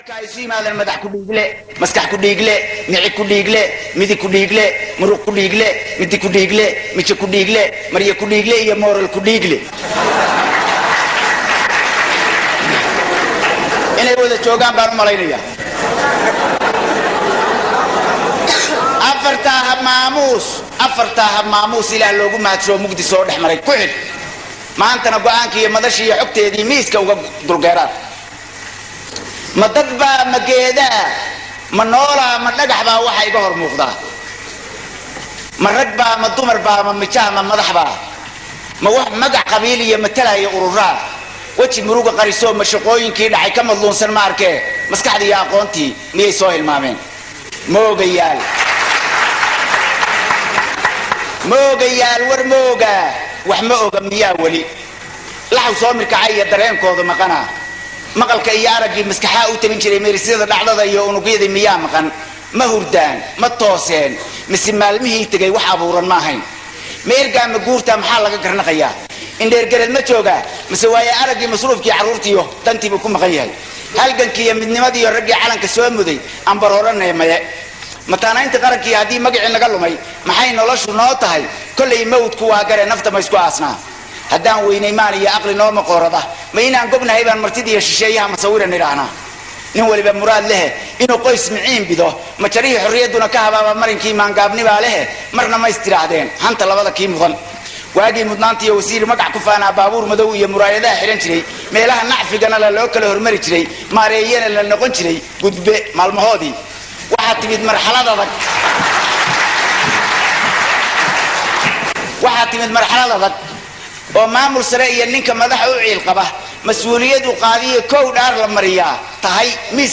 Dhegeyso: Gabay Ka Hadlaya Siyaasiga Somaliyeed oo uu Tiriyey Alaha u Naxariistee Abwaan C/qaadir Xersi Yam Yam 21 Sano Ka Hor